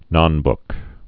(nŏnbk)